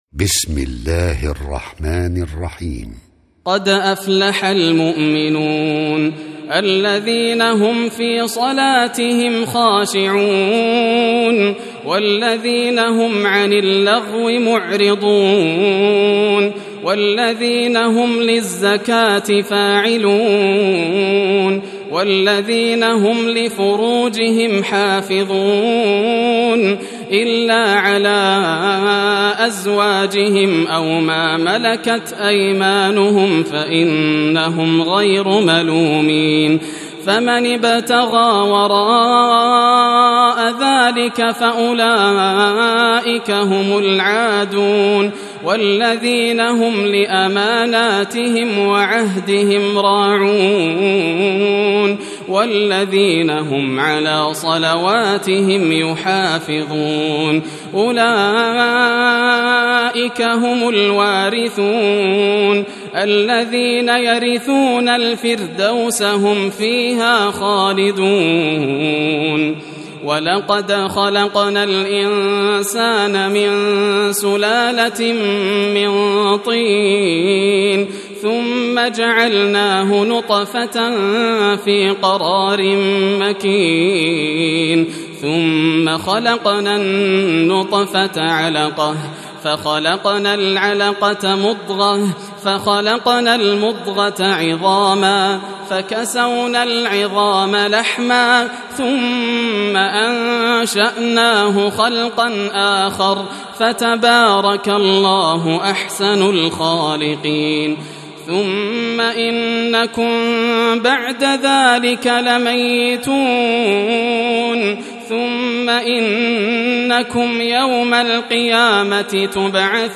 سورة المؤمنون > المصحف المرتل للشيخ ياسر الدوسري > المصحف - تلاوات الحرمين